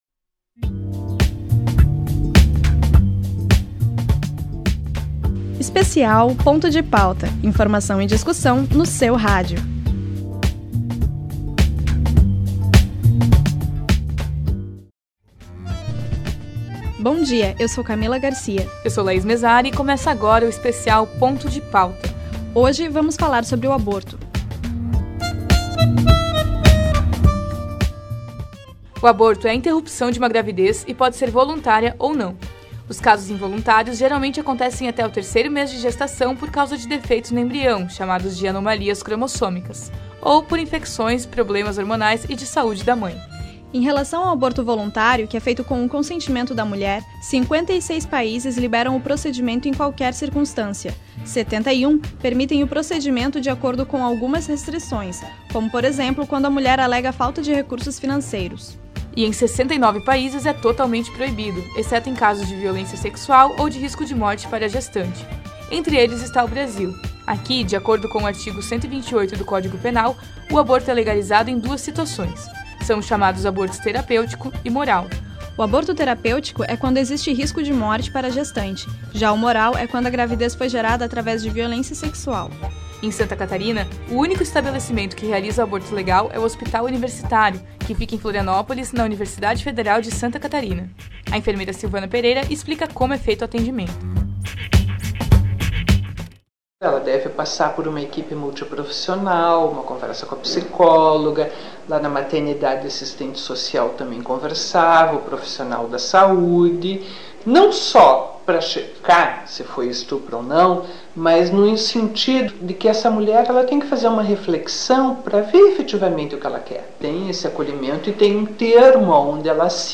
Abstract: Programa que trabalha com o tema aborto a partir do ponto de vista médico/legal. Contém entrevistas com médicos de opiniões diversas sobre o assunto e também com mulheres que já realizaram o procedimento ou são contrárias a ele.